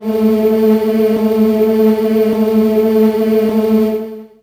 55be-syn10-a2.aif